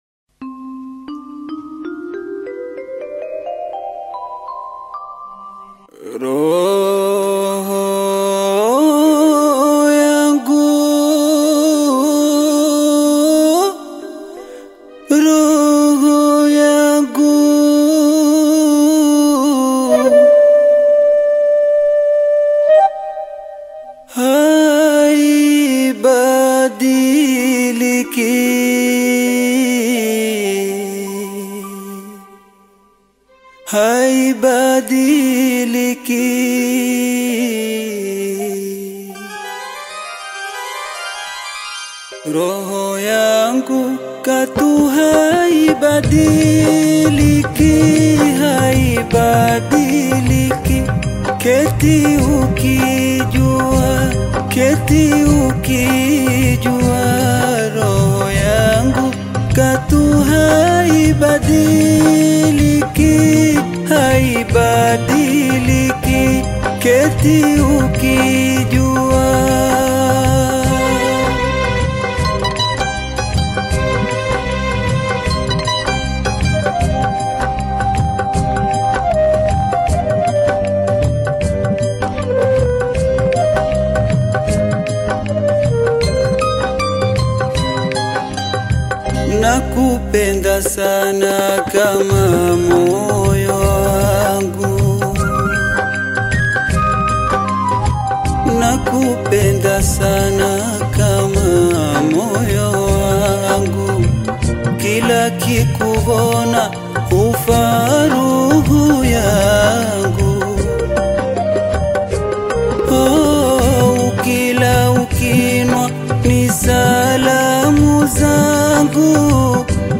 Tanzanian singer and songwriter based in Zanzibar
African Music